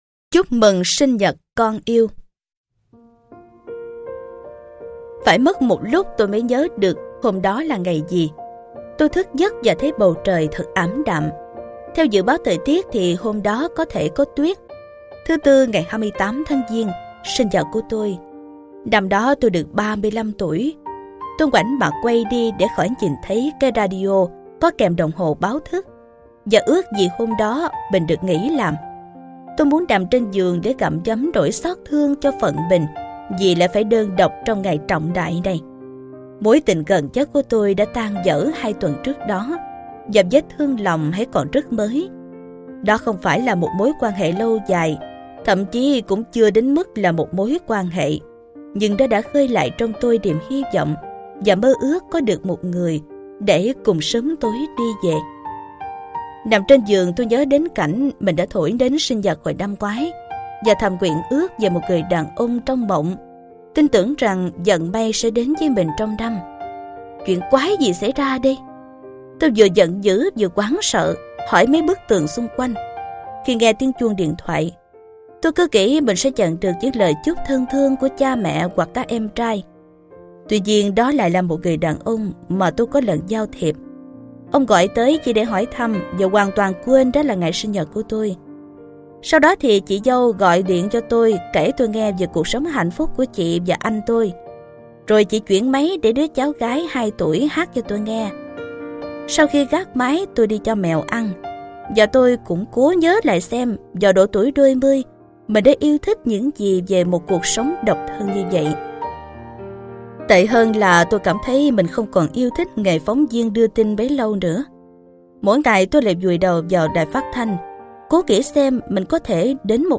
Sách nói Chicken Soup 9 - Vòng Tay Của Mẹ - Jack Canfield - Sách Nói Online Hay